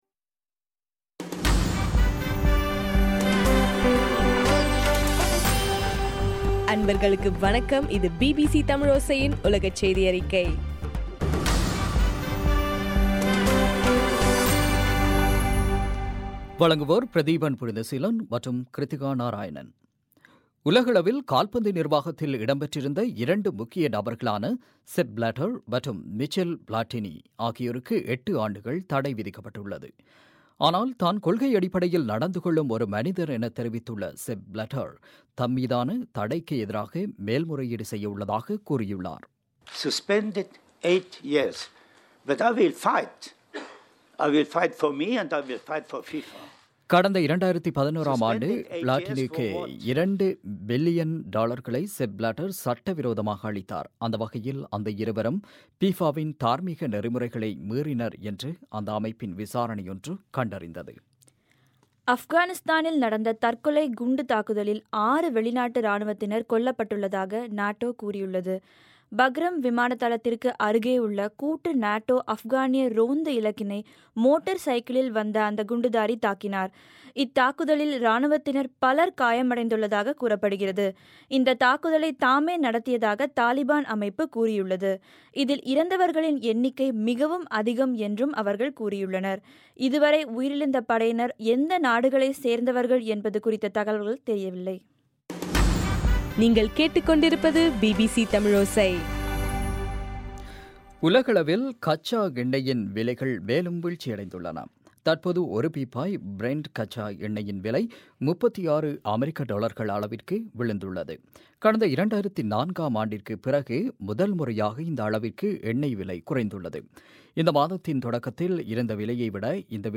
பிபிசி தமிழோசையின் உலகச் செய்தியறிக்கை